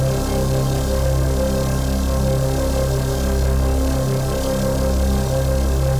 Index of /musicradar/dystopian-drone-samples/Non Tempo Loops
DD_LoopDrone1-C.wav